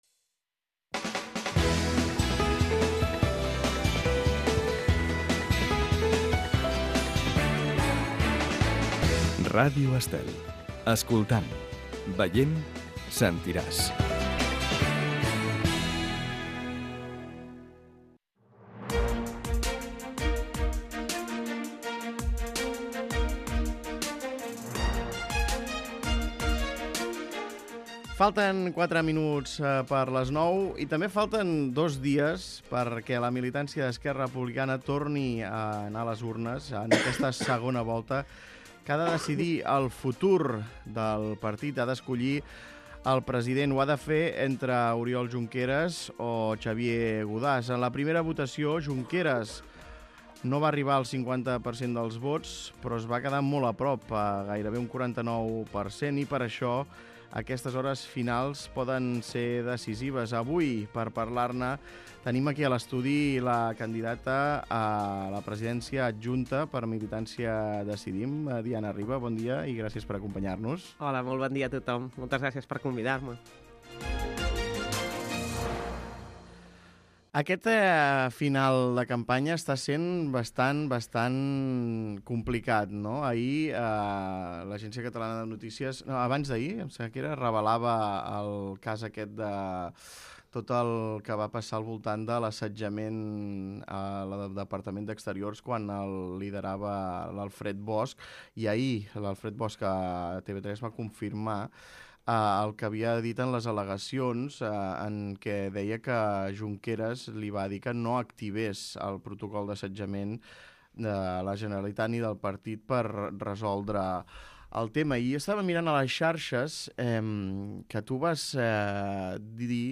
Escolta l'entrevista a Diana Ribas, eurodiputada i candidata adjunta a la presidència d'ERC per Militància Decidim